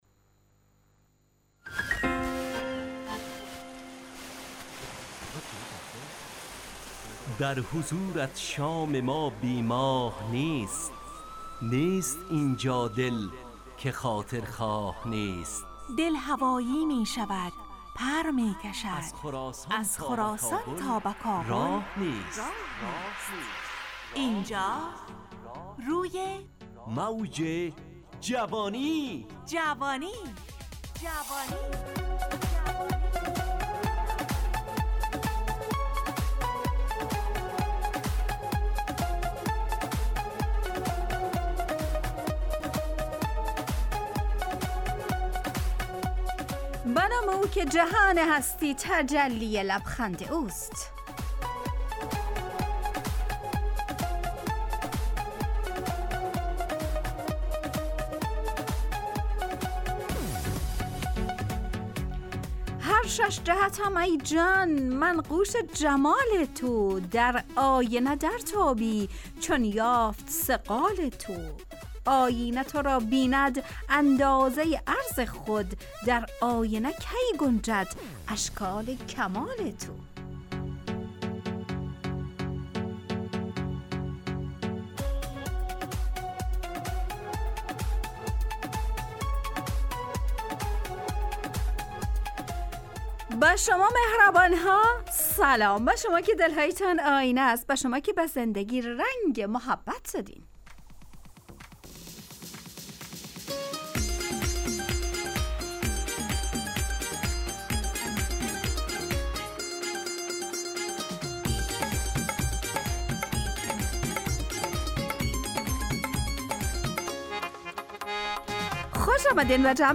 همراه با ترانه و موسیقی مدت برنامه 70 دقیقه .
برنامه ای عصرانه و شاد